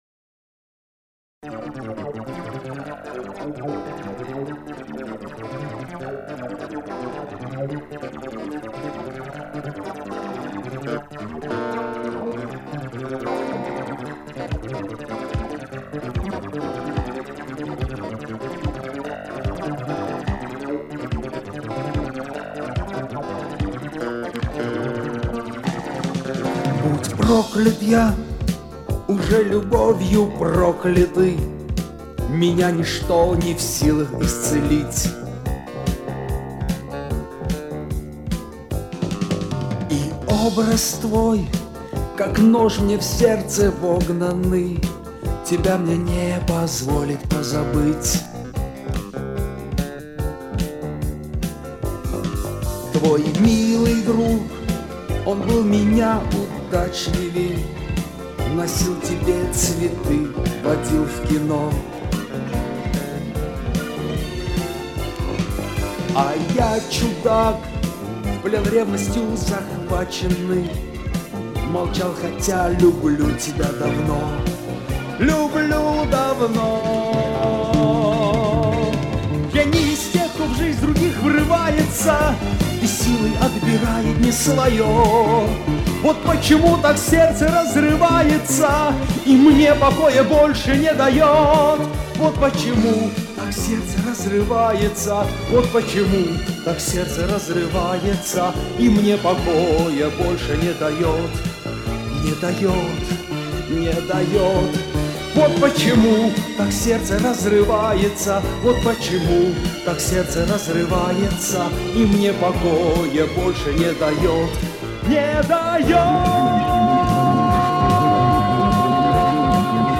Исходный трек не очень хорошего качества.